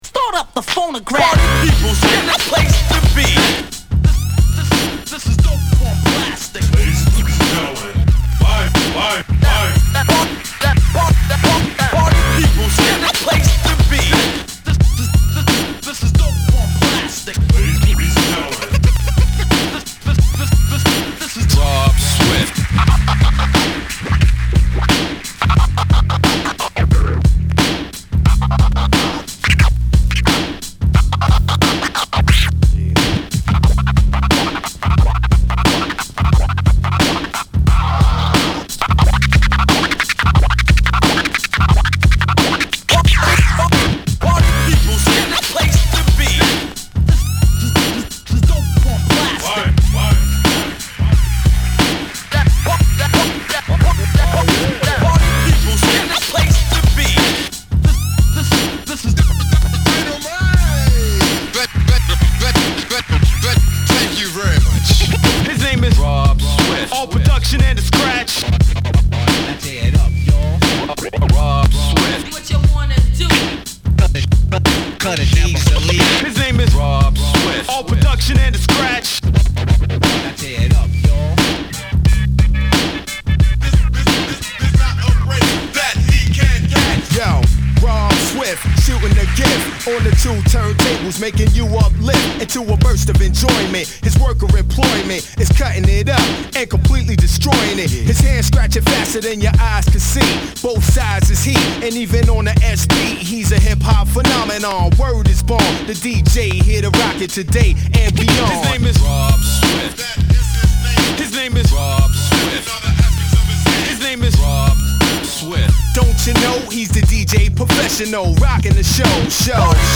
ホーム HIP HOP UNDERGROUND 12' & LP R